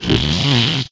yoshi_snore3.ogg